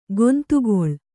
♪ gontugoḷ